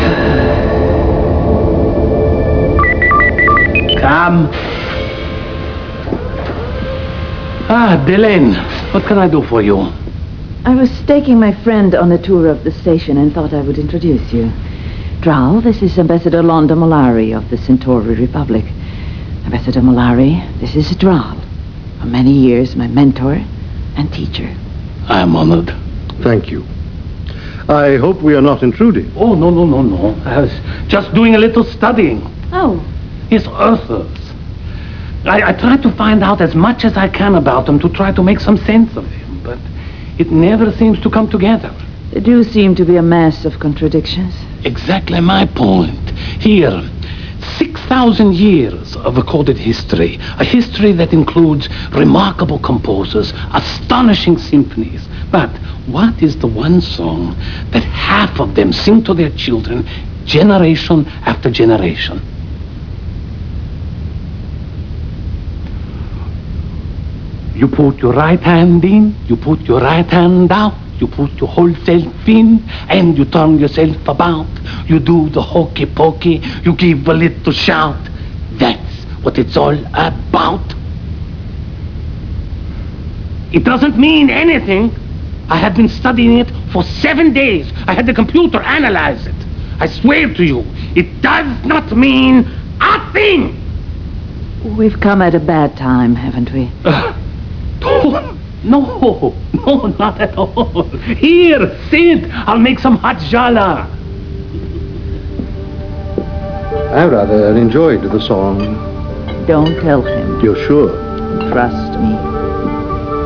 1.25M The exchange between Londo, Delenn and Draal regarding some strange human customs.